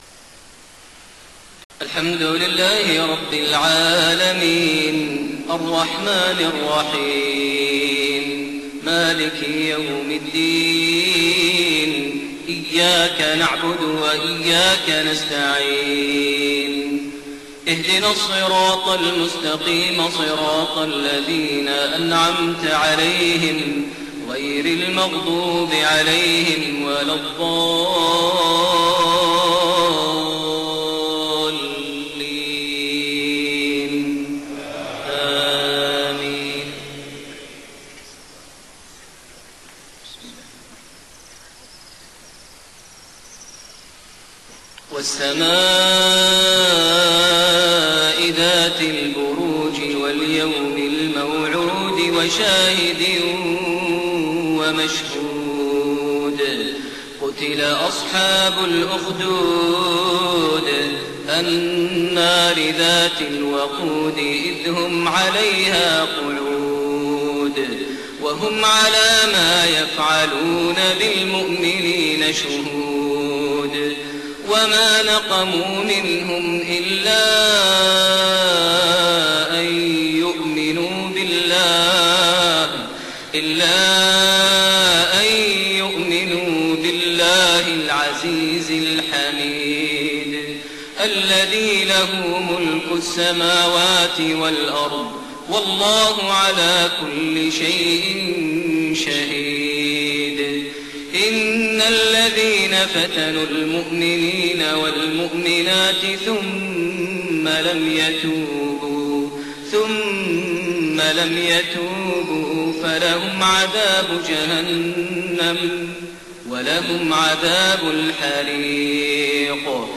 صلاة المغرب2-6-1432 سورة البروج > 1432 هـ > الفروض - تلاوات ماهر المعيقلي